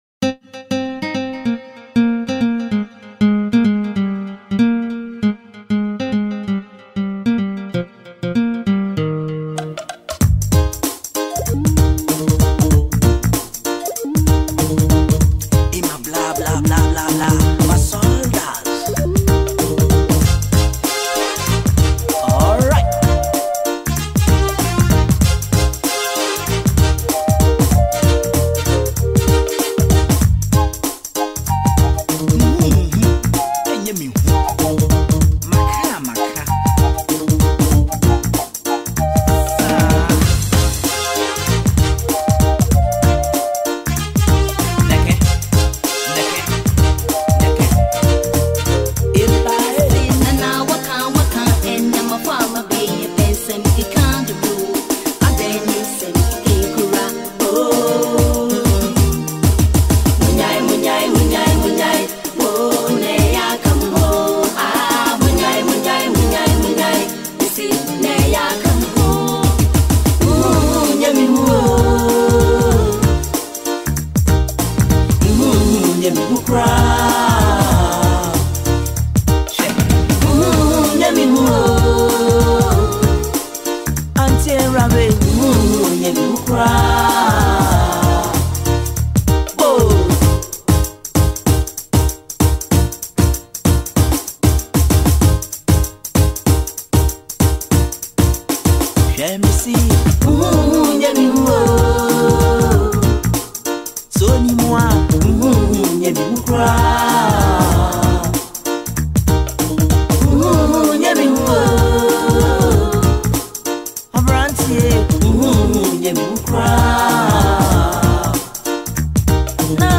90s Music
old highlife song